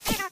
雷电精灵Electro Spirit最多可以一下击晕9个目标，语音很有电流感。